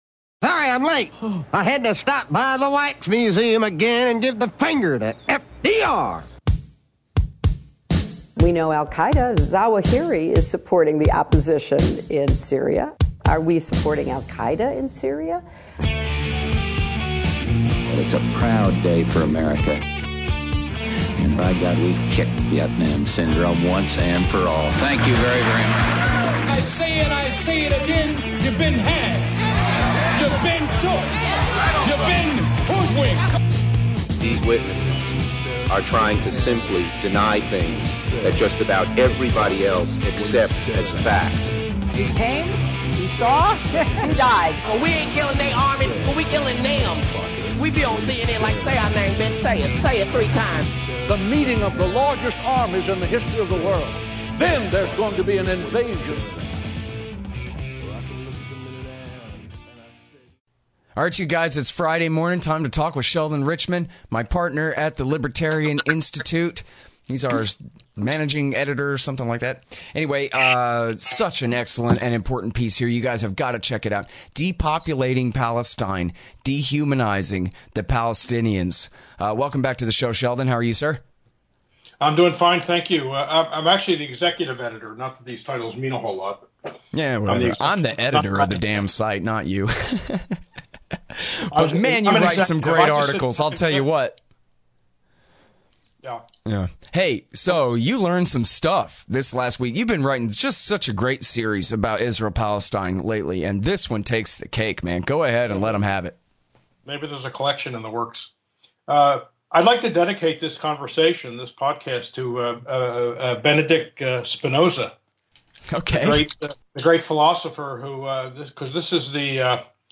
is interviewed on his new TGI